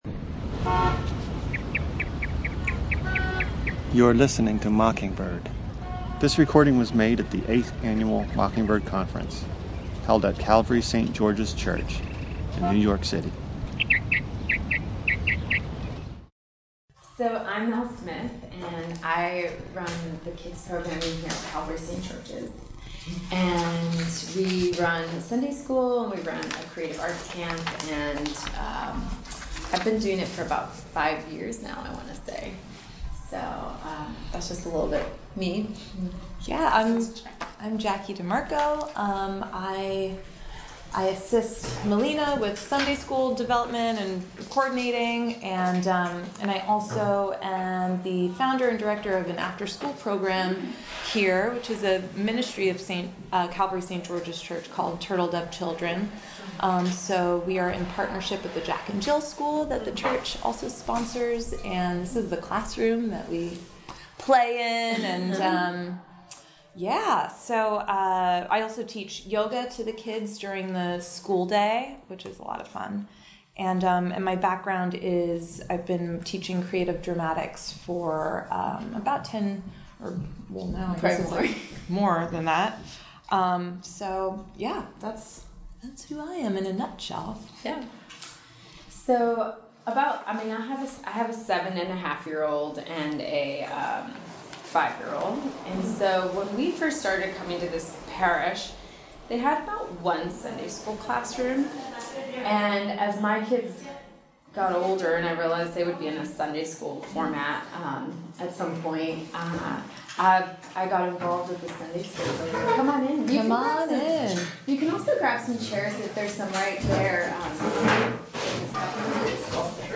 Venue: 2015 NYC Mockingbird Conference